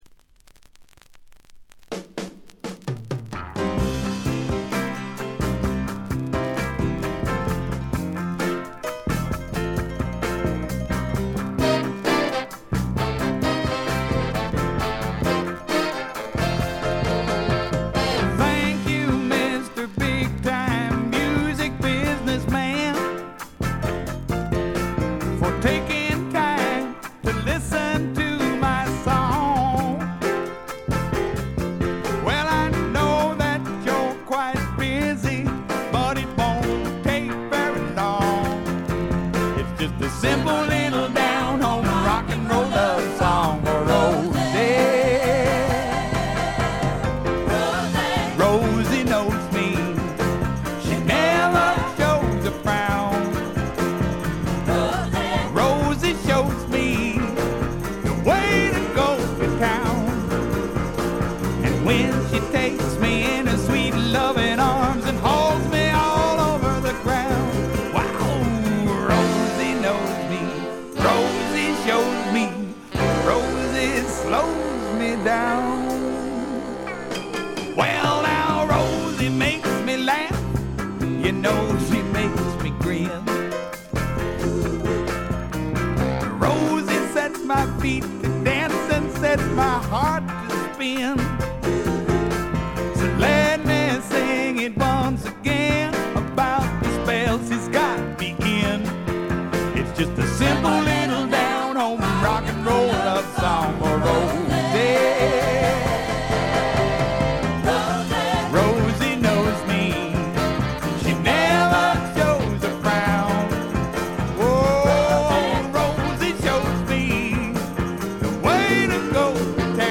バックグラウンドノイズ、チリプチ多め（特にB1あたり）。散発的なプツ音少し。
スワンプ系シンガーソングライター作品の基本定番。
試聴曲は現品からの取り込み音源です。
Vocals, Acoustic Guitar